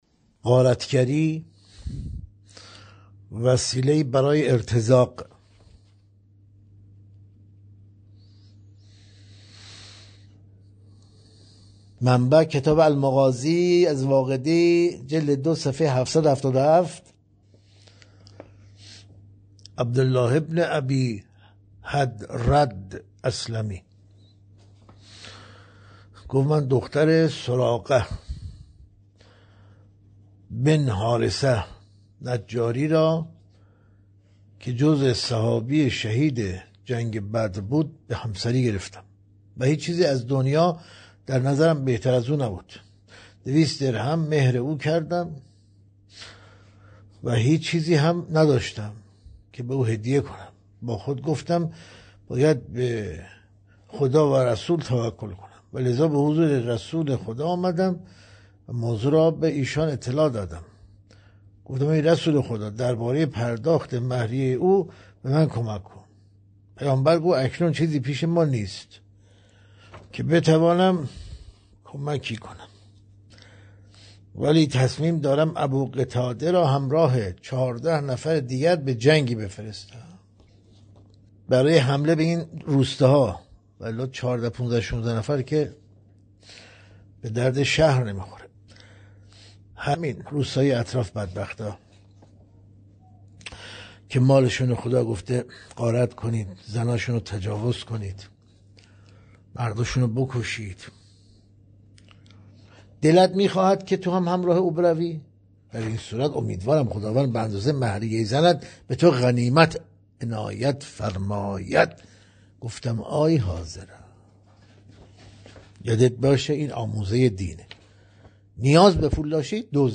گزیده‌ای از تدریس‌های روزانه